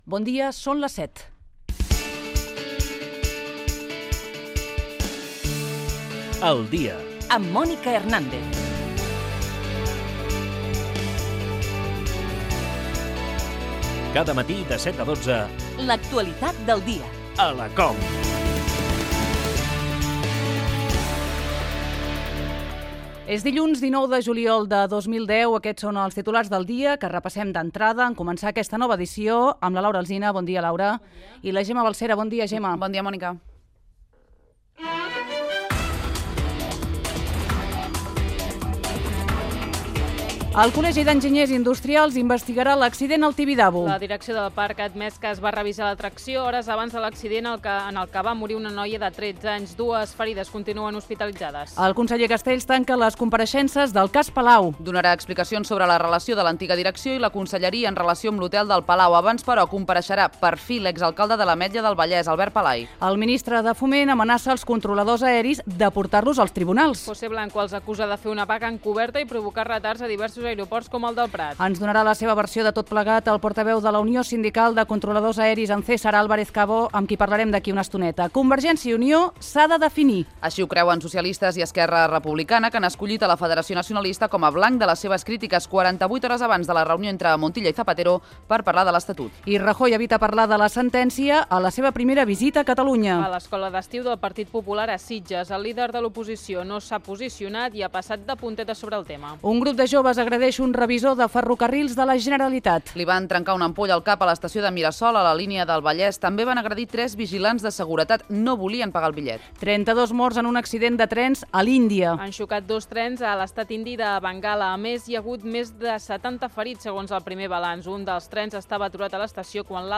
Hora, careta, data, titulars informatius , el temps, connexions amb el RAC i Transmet
Info-entreteniment
Fragment extret de l'arxiu sonor de COM Ràdio.